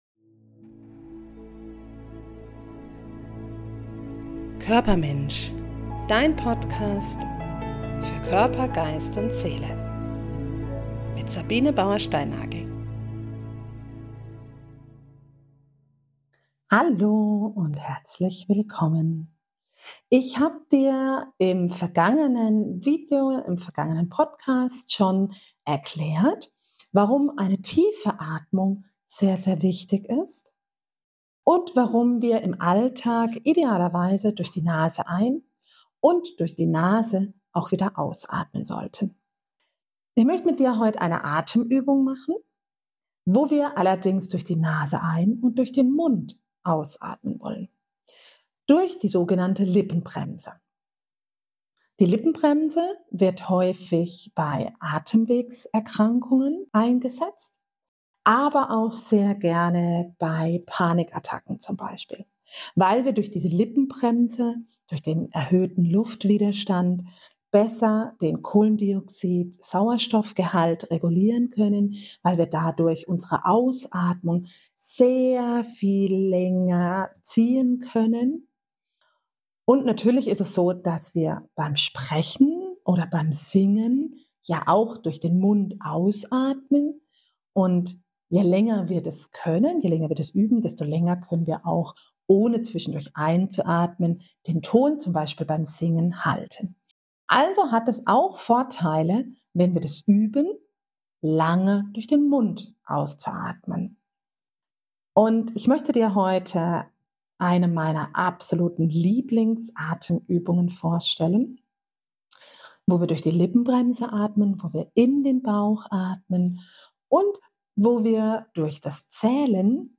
Ich leite Dich an, tief in den Bauch zu atmen und Dich ganz auf Deine Atmung zu konzentrieren. Diese Übung dient als kleine Achtsamkeitsübung und kann vor allem bei Panikattacken sehr hilfreich sein.
Atemuebung_Luftballon(2).mp3